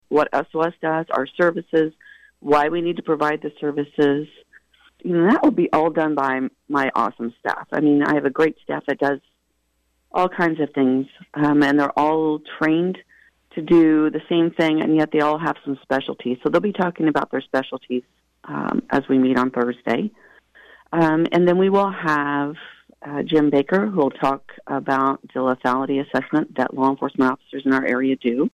recently joined KVOE’s Morning Show to preview what each session will entail.